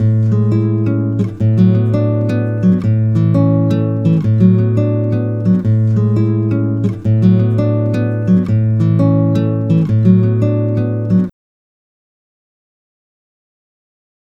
(fs,x) = read('../audio/AcousticGuitar.wav')